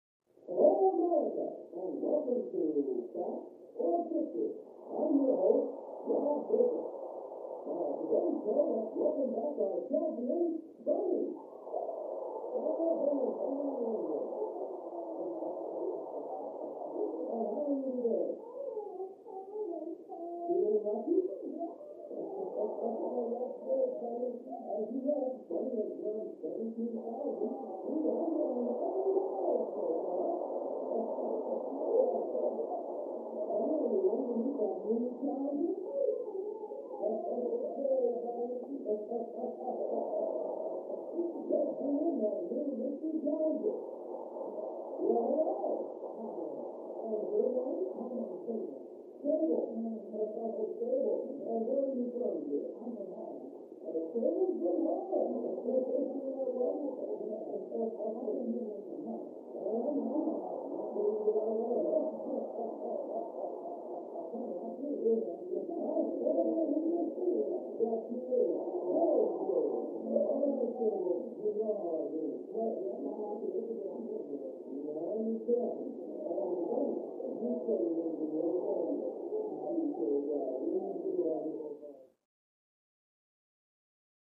Television; Full Game Show With Announcer, Crowd And Players. Through Thick Wall.